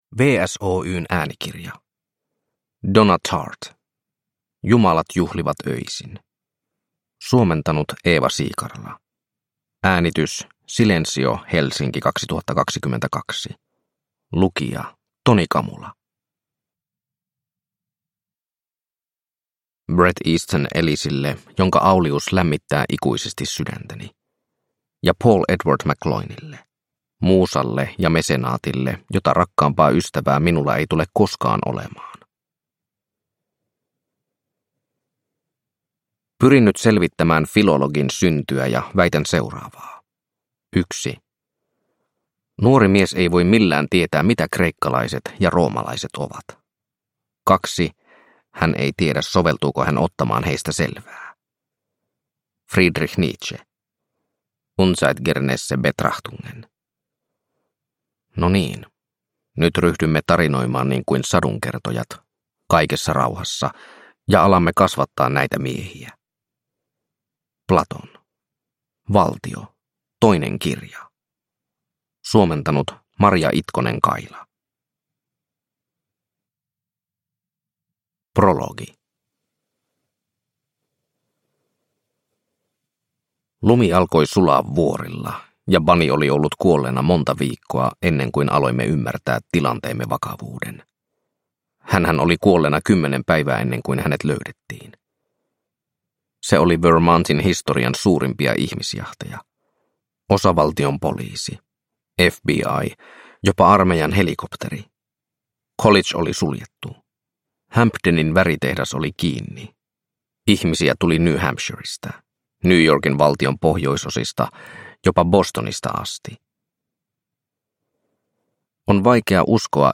Jumalat juhlivat öisin – Ljudbok – Laddas ner